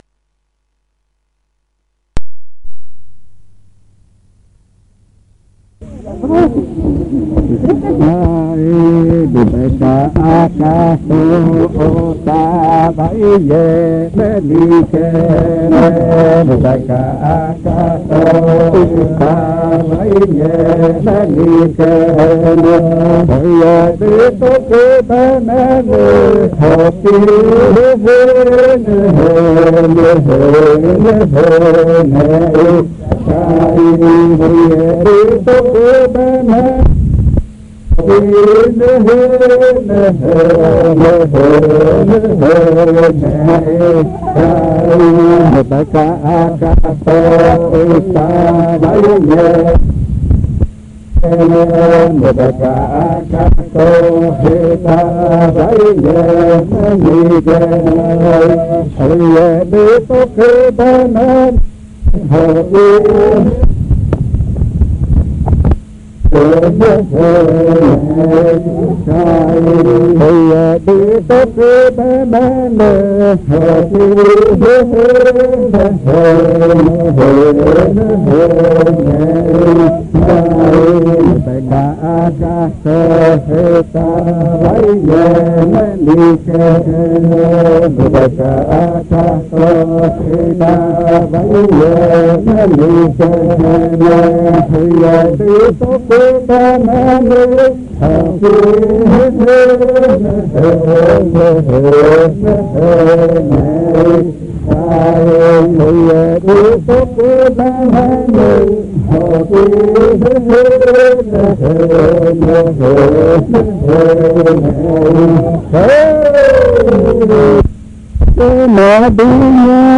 Canciones del baile de Illo majtsi
El audio incluye los lados A y B del casete .